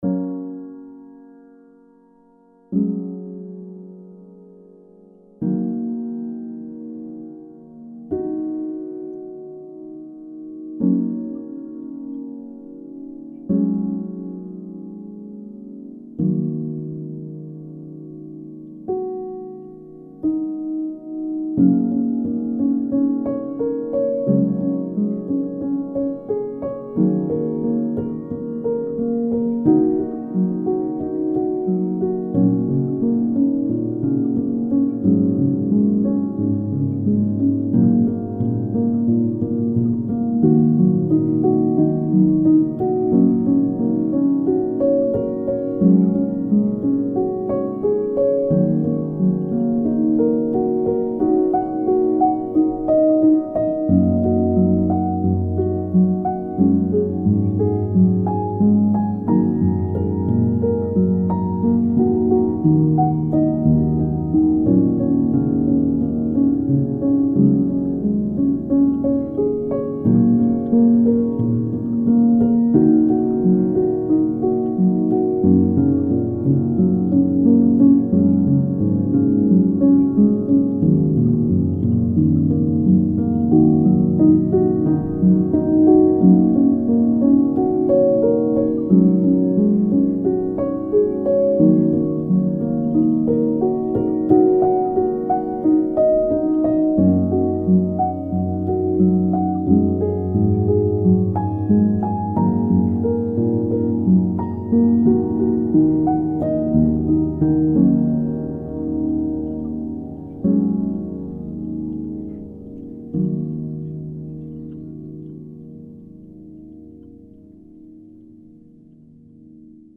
سبک آرامش بخش , پیانو , مدرن کلاسیک , موسیقی بی کلام
پیانو آرامبخش